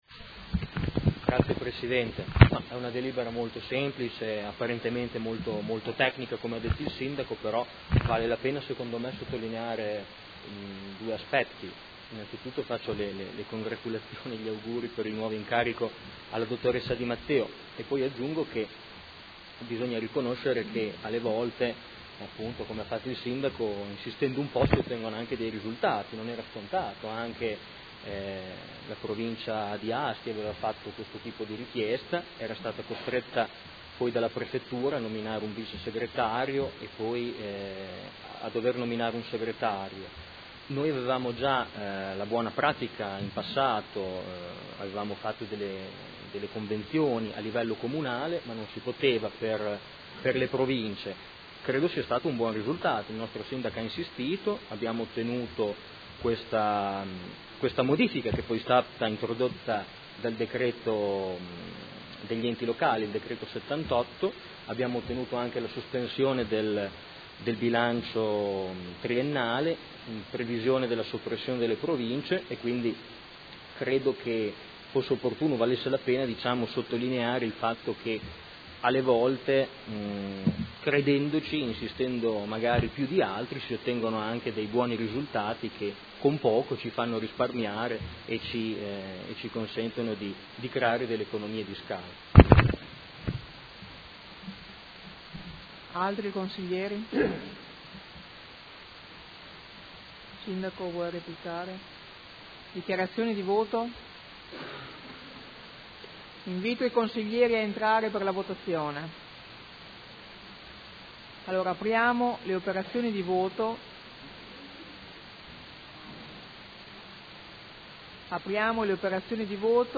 Proposta di deliberazione: Convenzione tra il Comune di Modena e la Provincia di Modena per il servizio in forma associata delle funzioni di Segretario. Dibattito